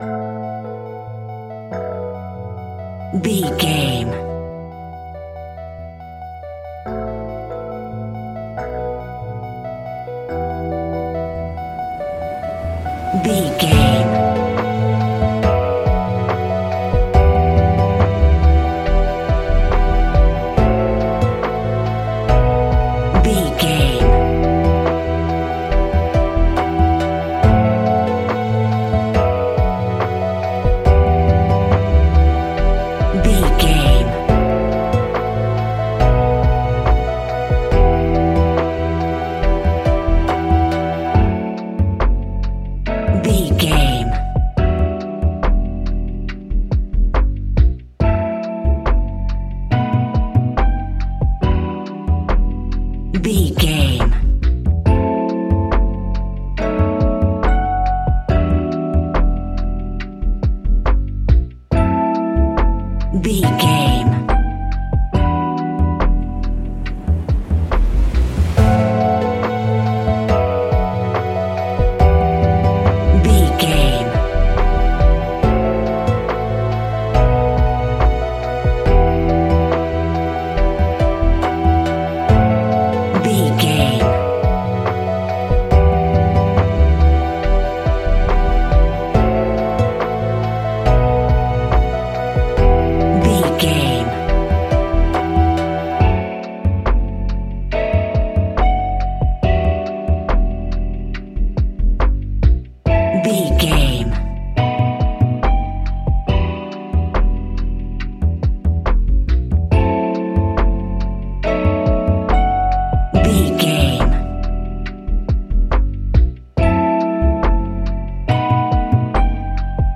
Ionian/Major
E♭
laid back
Lounge
sparse
new age
chilled electronica
ambient
atmospheric
instrumentals